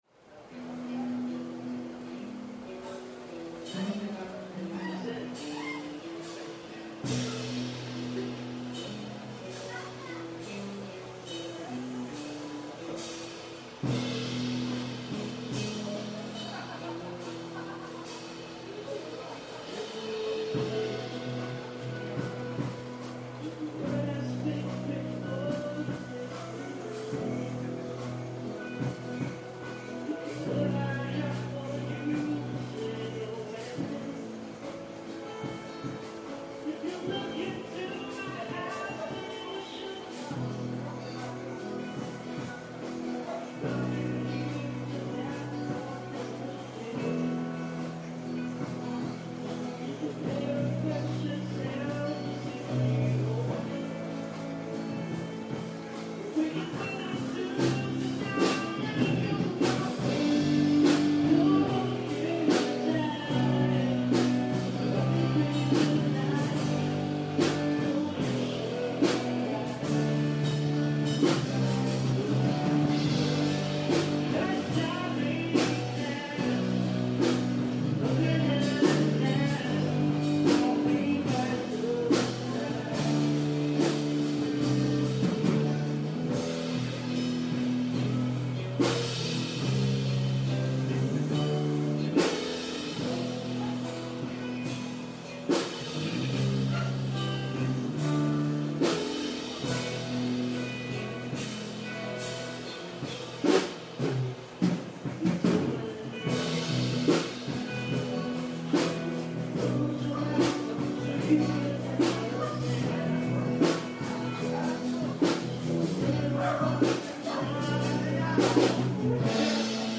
performed by the band from the neighborhood last night.